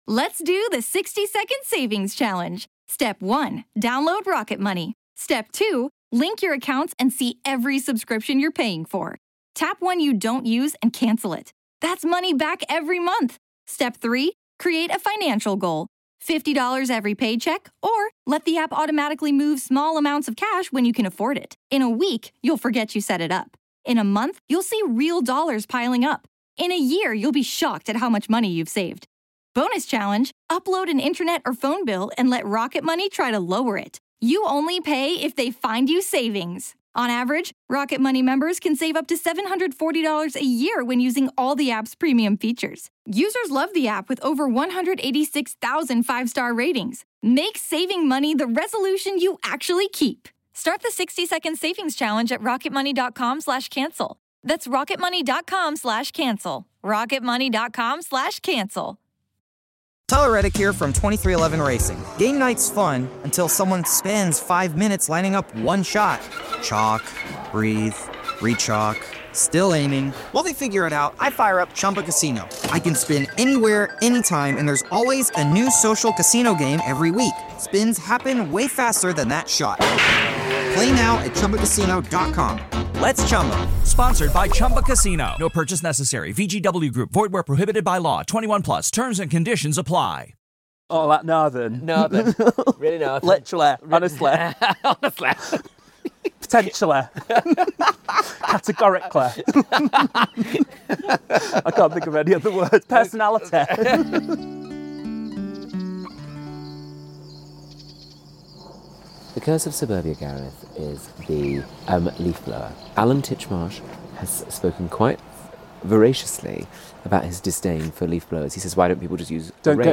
joins me in the garden for a delightfully fun, and occasionally naughty, chat